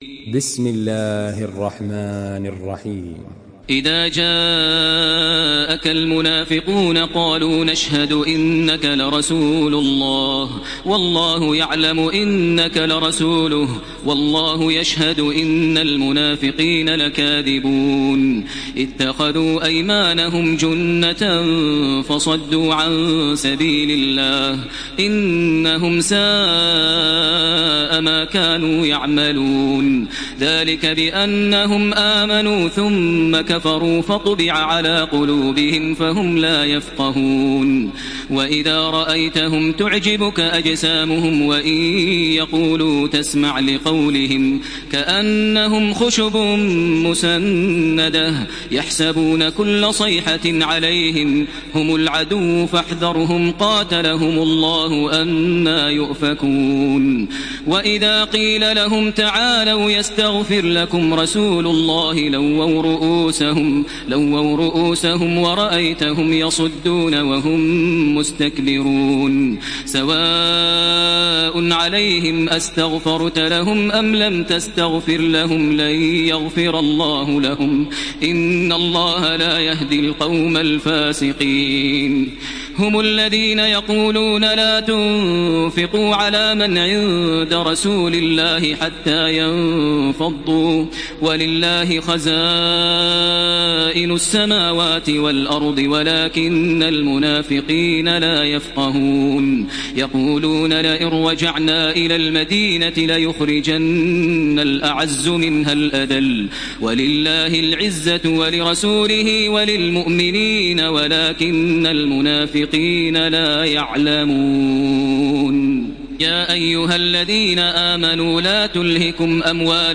Surah আল-মুনাফিক্বূন MP3 by Makkah Taraweeh 1433 in Hafs An Asim narration.